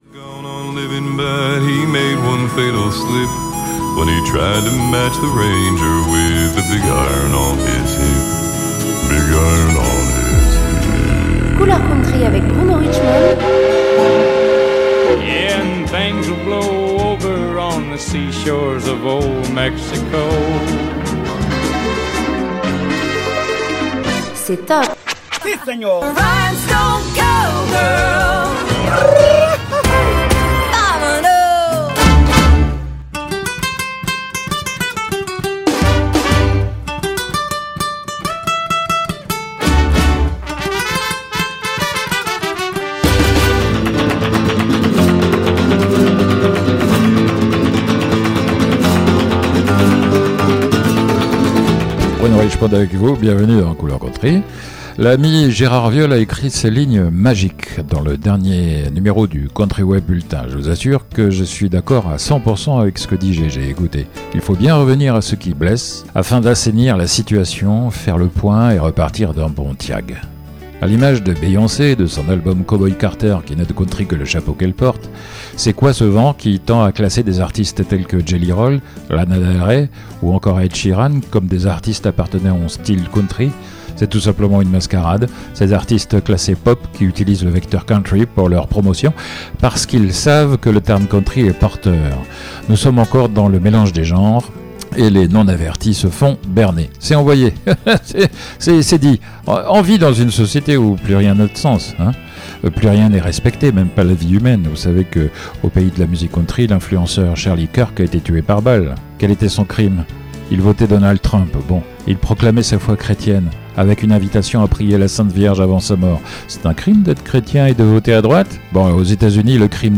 Vous aurez, en musique, un aperçu de leurs beaux travaux.